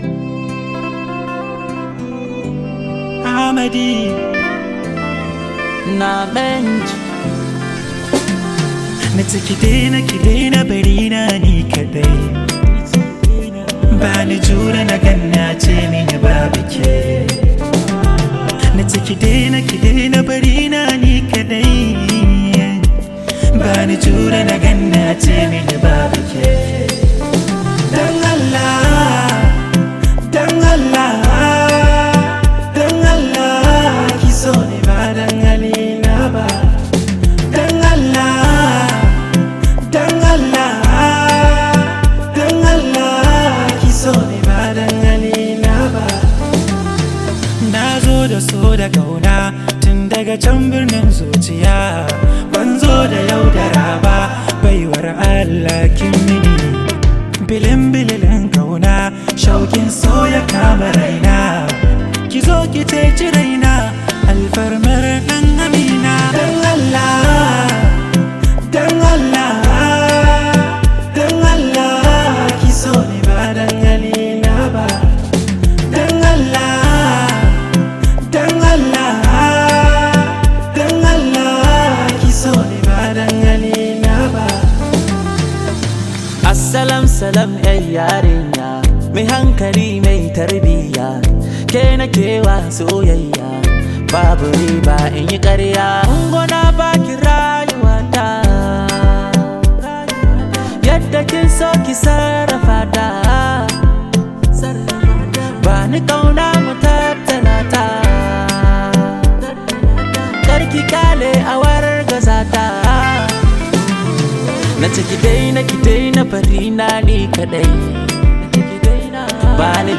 hausa song
This high vibe hausa song